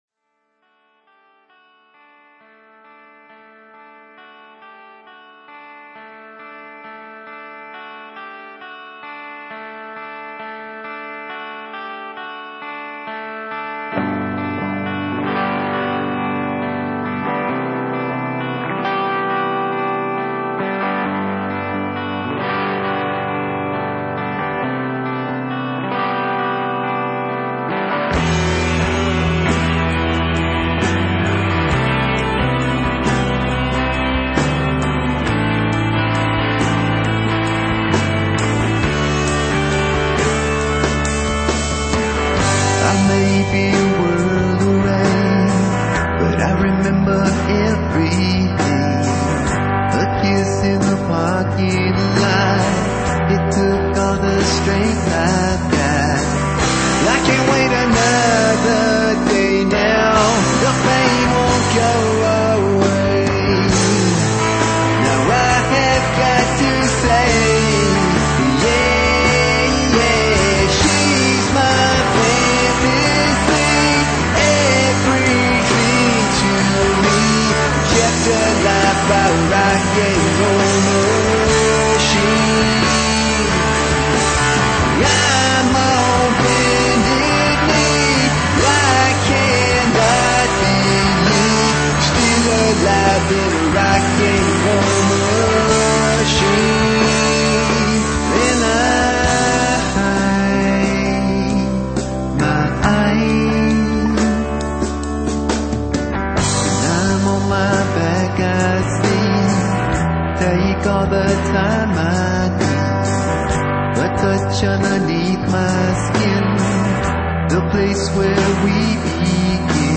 punk
metal
high energy rock and roll